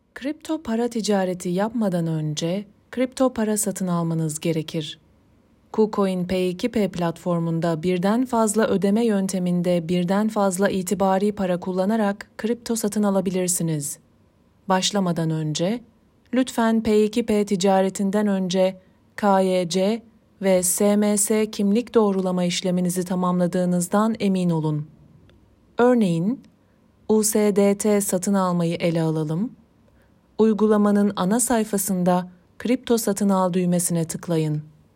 个性舒缓